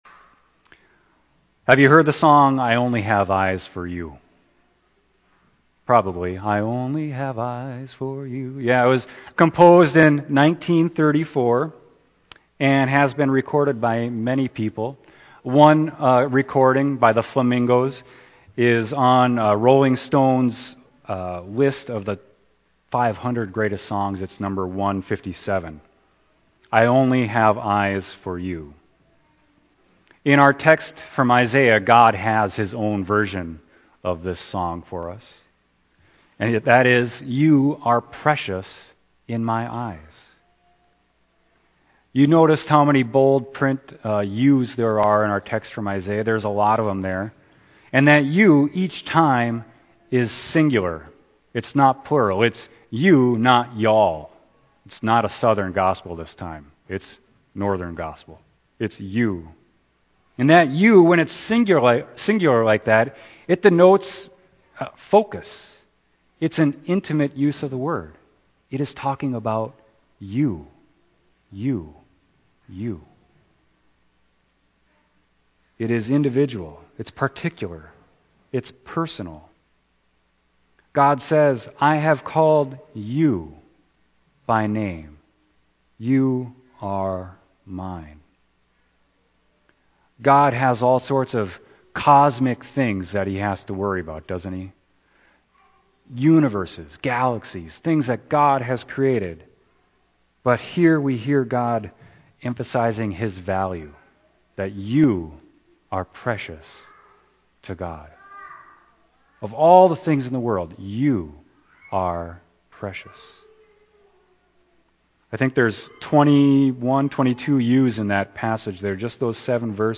Sermon: Isaiah 43.1-7